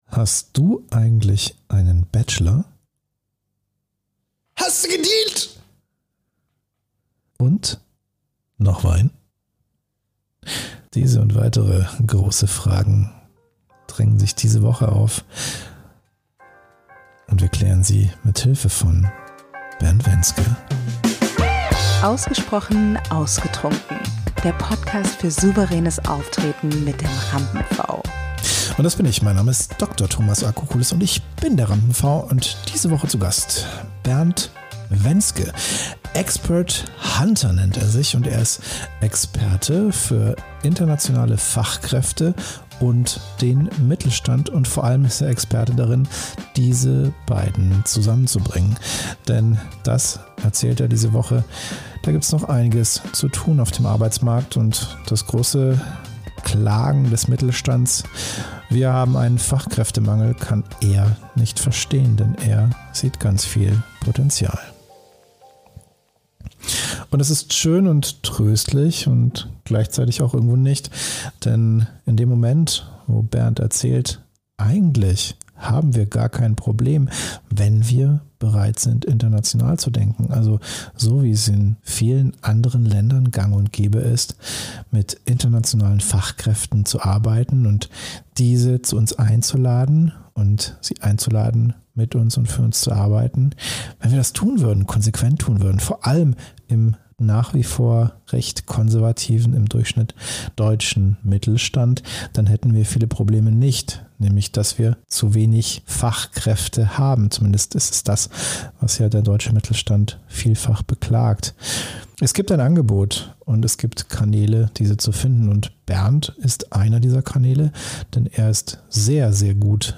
Format: Audio-Podcast, Gespräch